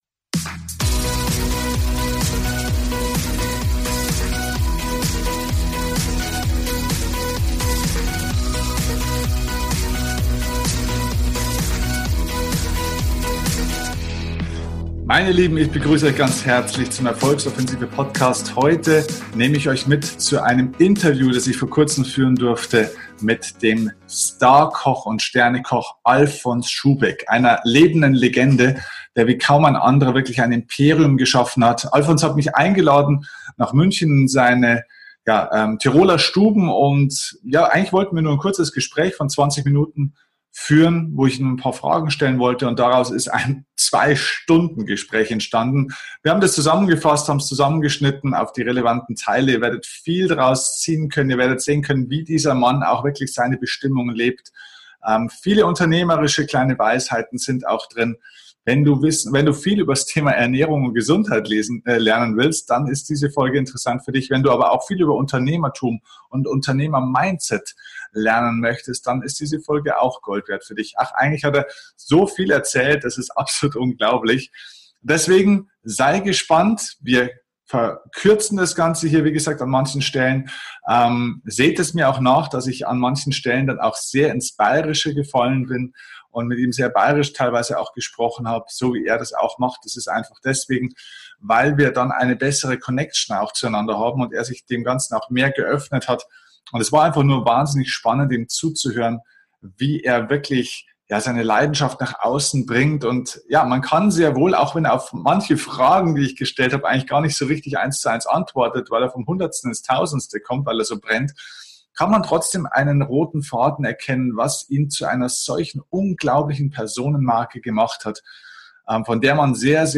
Heute ist er Gast im ERFOLGSOFFENSIVE Podcast und nimmt kein Blatt vor den Mund. Mit seiner direkten Art spricht er über seinen Werdegang als Koch, als Unternehmer und als Erfolgsmensch. Er hat mich in eines seiner Restaurants eingeladen und wir haben ein tolles Gespräch über Unternehmertum, Chef sein, Routinen und ihn als Person geführt.